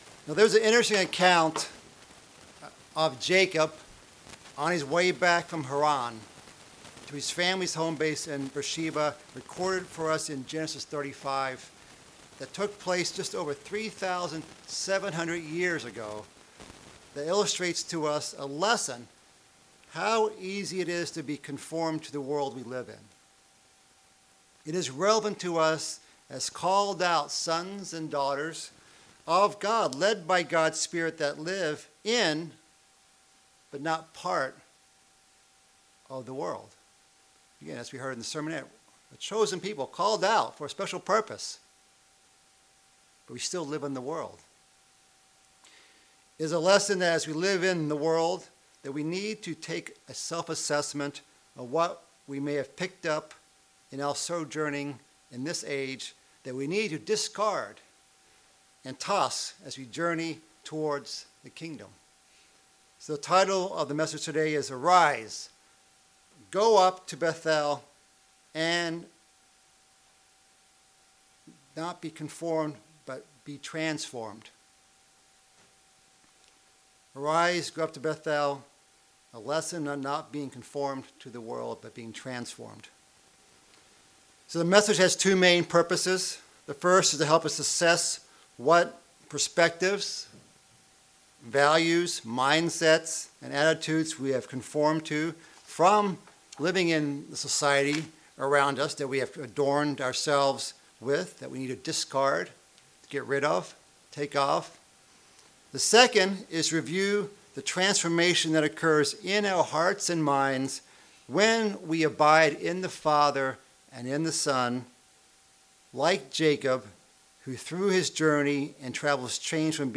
Using the history of Jacob, we bring the lessons of the past, to light in the future--our present--as the sermon unfolds into how we need to transform our minds, and be vigilant, as to what we absorb from the world.
Given in Knoxville, TN